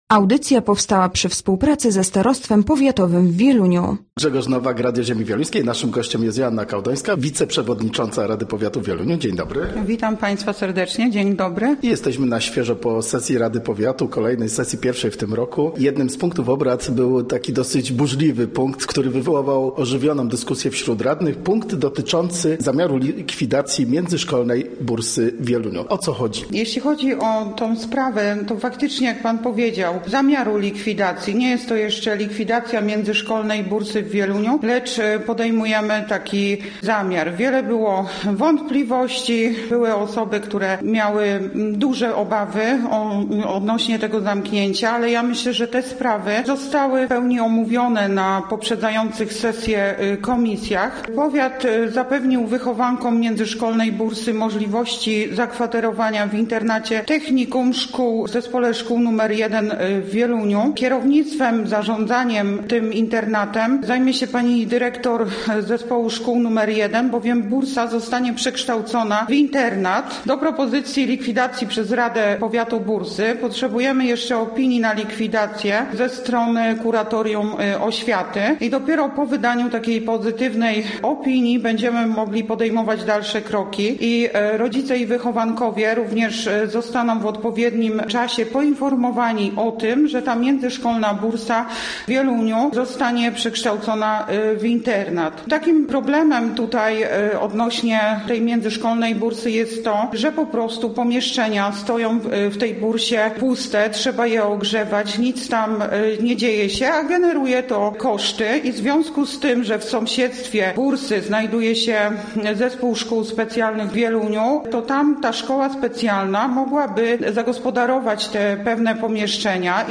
Gościem Radia ZW była Joanna Kałdońska, wiceprzewodnicząca Rady Powiatu w Wieluniu